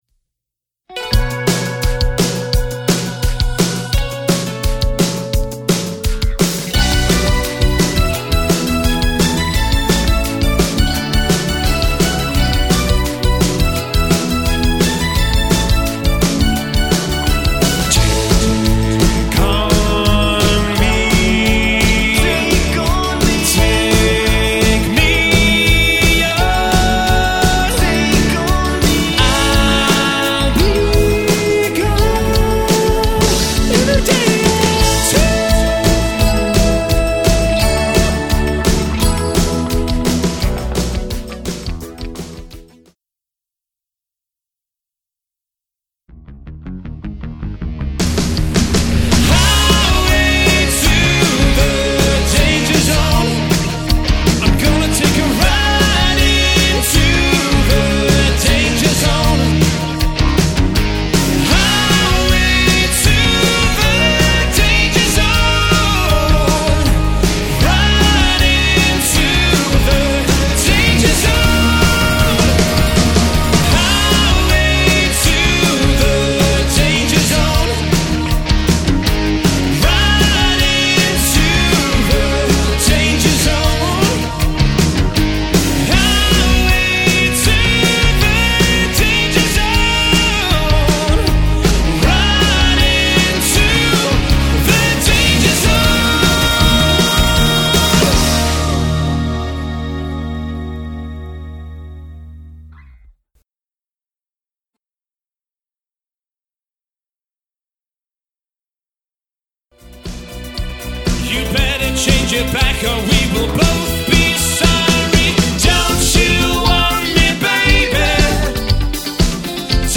• 4-piece